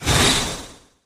Swing SFX
挥拳音效
CR_monk_atk_swing_01.mp3